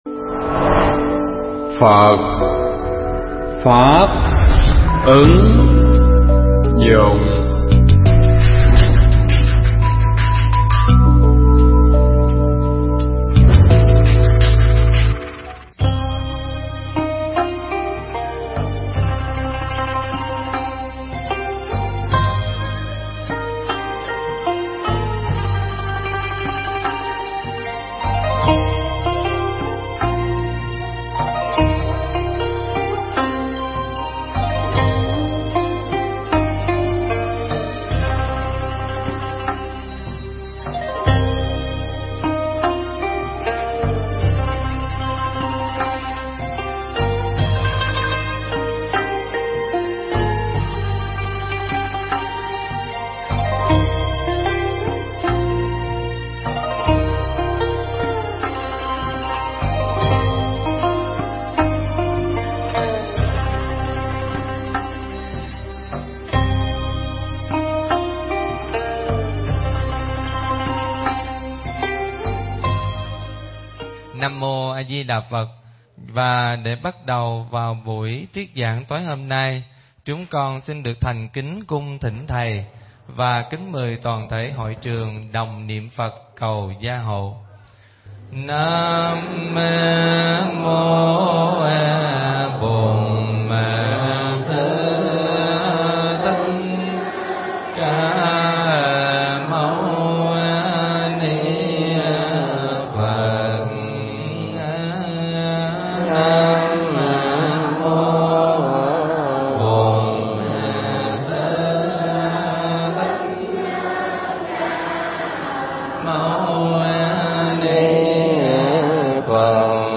Bài pháp âm Kinh Pháp Cú Phẩm Phật Đà (Câu 179 đến 182)
giảng tại tu viện Tường Vân